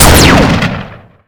gun1.wav